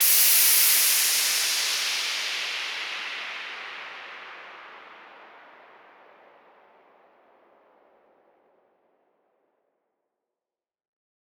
Index of /musicradar/shimmer-and-sparkle-samples/Filtered Noise Hits
SaS_NoiseFilterA-01.wav